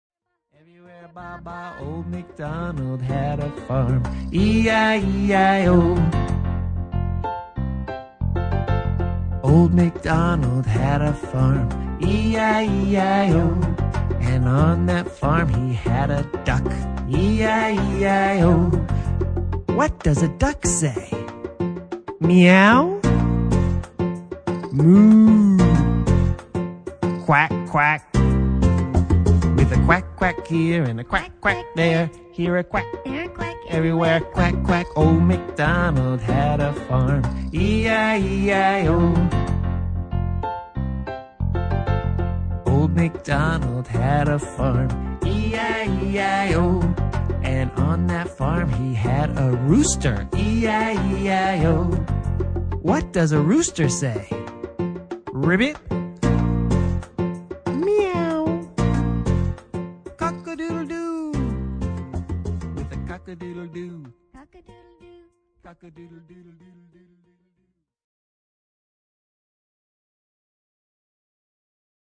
Old MacDonald (fast) (fast)
old-macdonald-fast.mp3